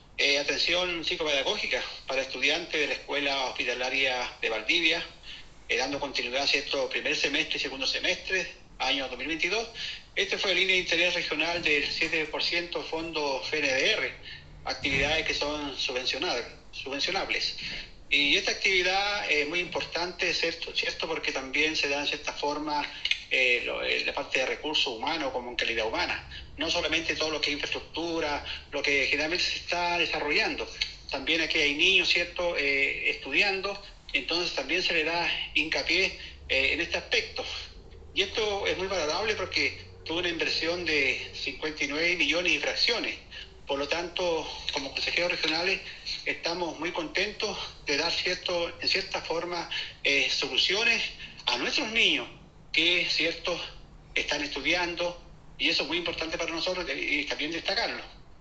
A su turno, el Consejero Regional, Carlos Duhalde, expresó que, “como Consejo Regional estamos muy preocupados de generar iniciativas que propendan a aportar al crecimiento y desarrollo humano.